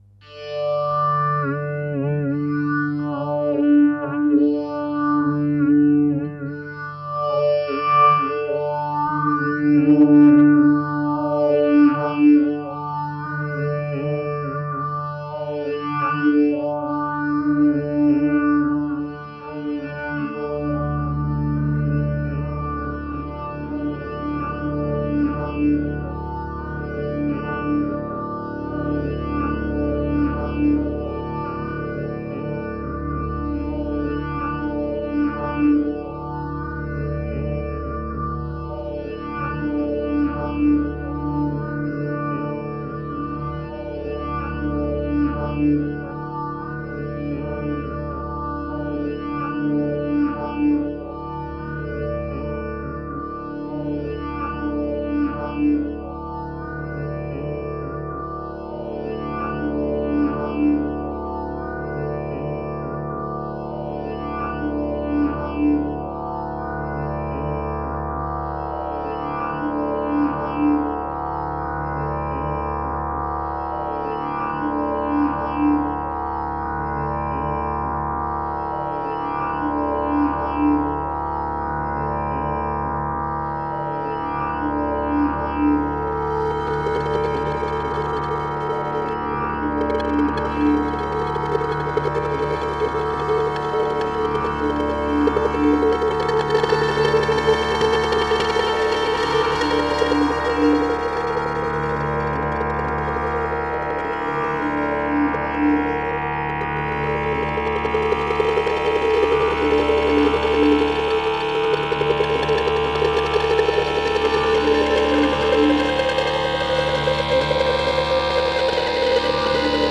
A new improvisation born in the midst of a summer storm.
electric guitar, various effect processing
Max/MSP laptop Driving the Storm Back